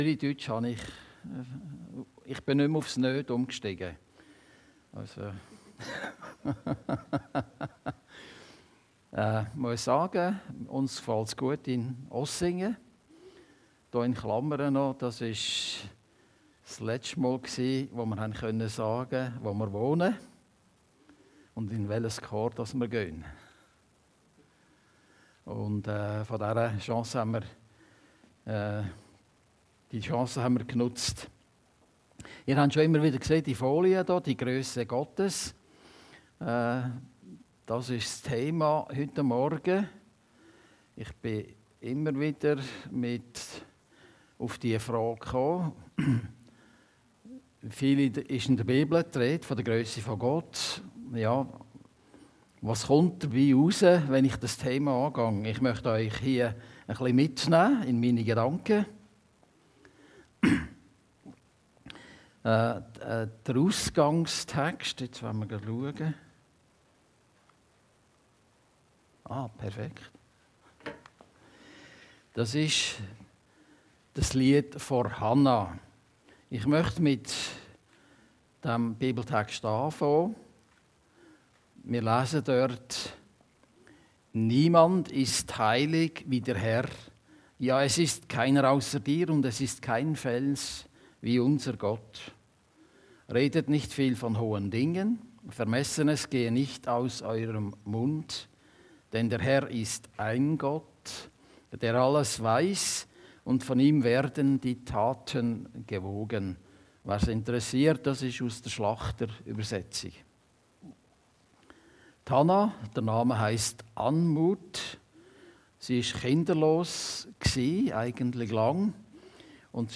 Predigten Heilsarmee Aargau Süd – Die Grösse Gottes